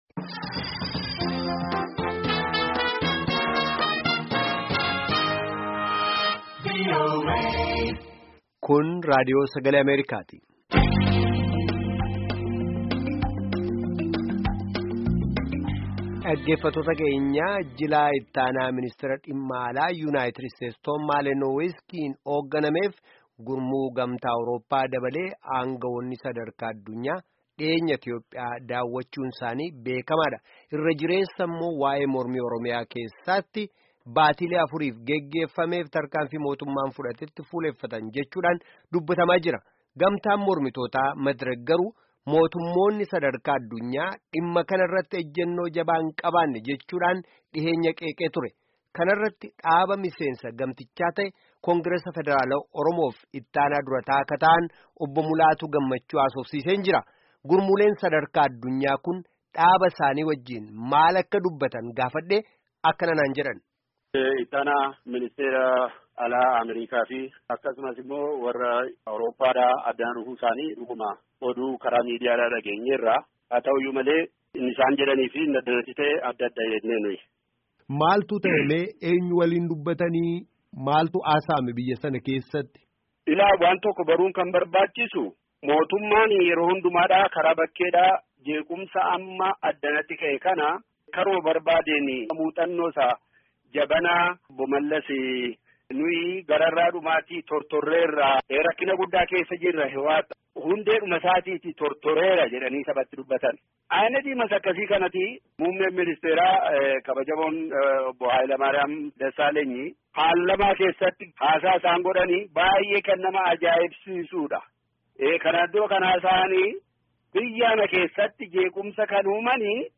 gaaffii fi deebii